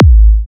edm-kick-49.wav